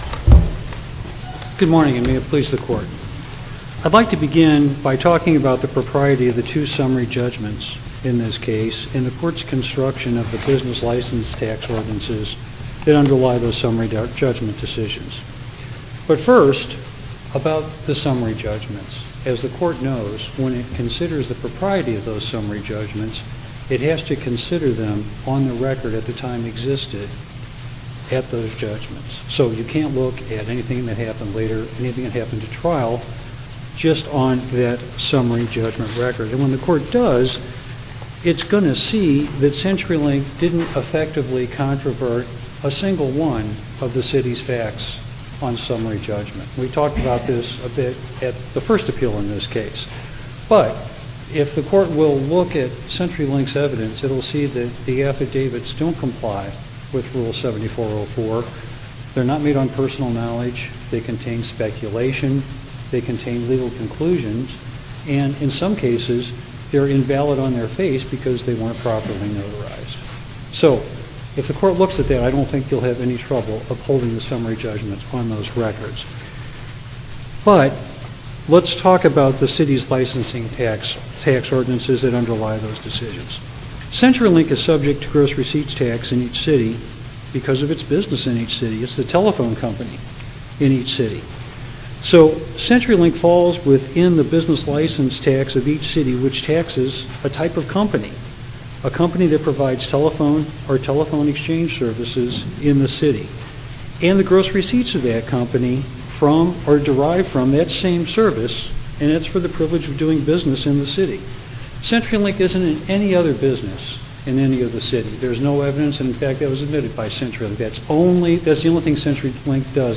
MP3 audio file of arguments in SC96276